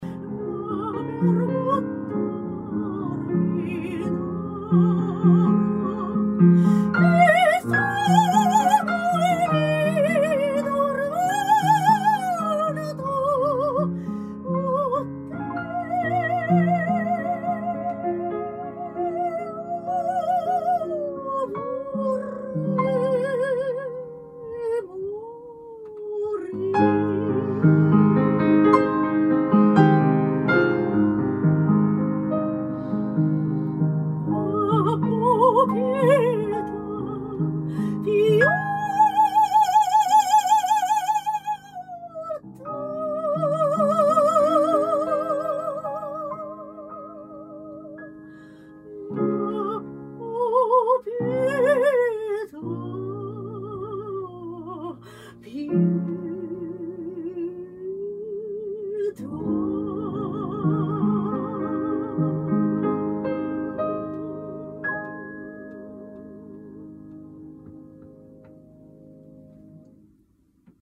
今回の収録は、私たち音楽家がスマートフォンを使用した場合というコンセプトで行いました。
（マイク：BeyerDynamic  TG V70d ／オーディオインターフェイス：TASCAM ixz）
3_BeyerDynamic-TOURING-GEARシリーズ-ダイナミック型マイクロホン-ハイパーカーディオイド-TG-V70d.mp3